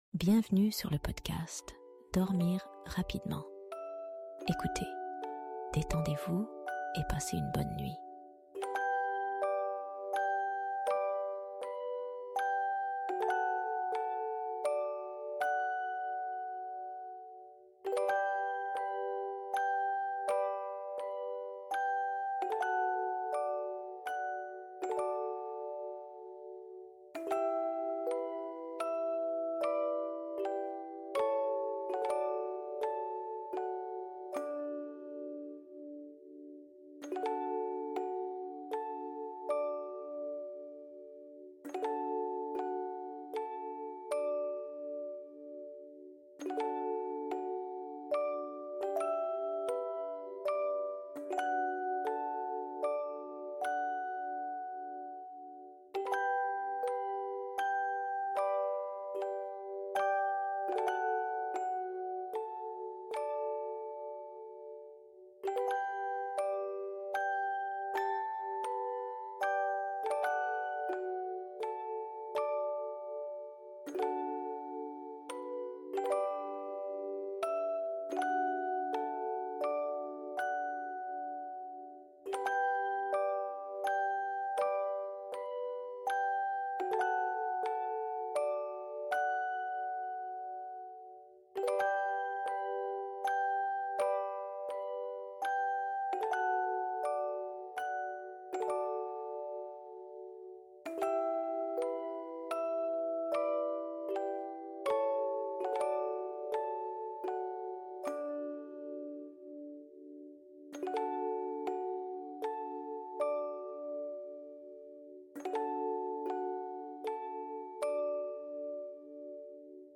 SOMMEIL Profond Induit par le Son Tendre d'une BERCEUSE